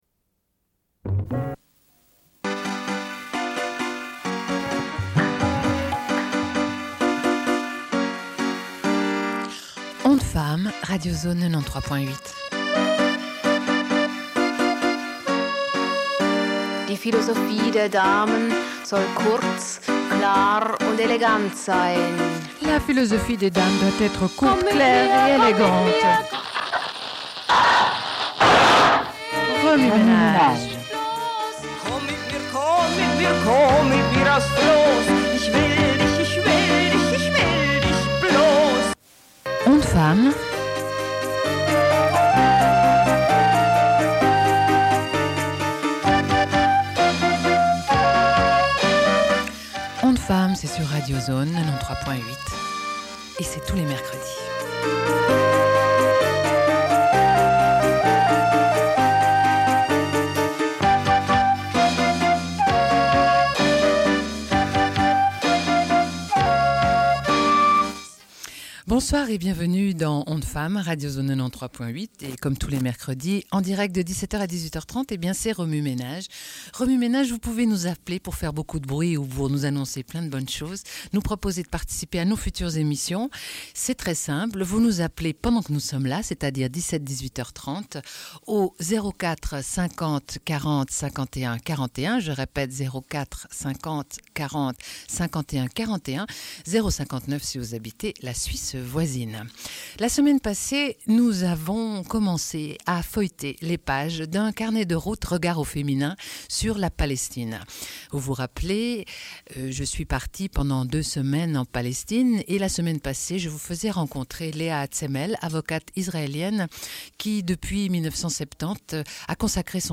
discussion en direct
Une cassette audio, face A
Radio